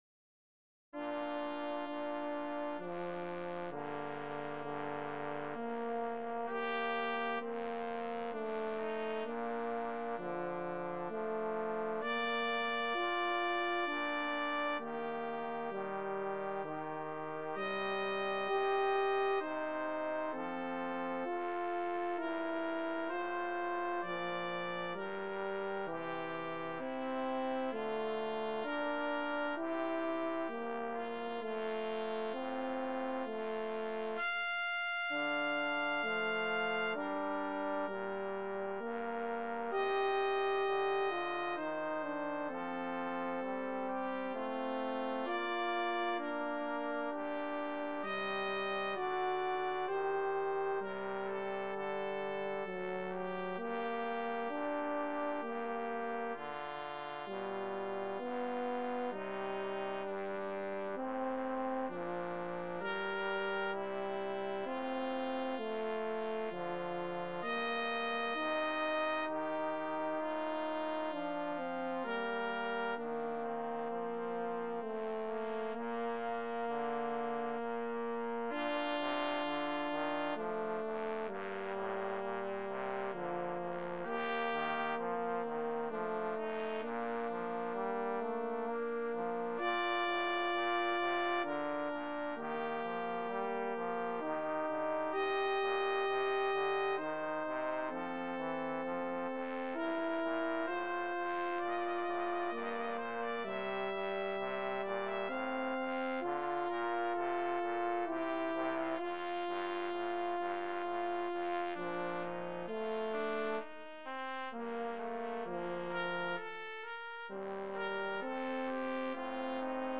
Voicing: Brass Trio